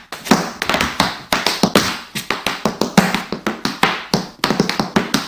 Play, download and share tap dance soundz original sound button!!!!
tap-dance-soundz.mp3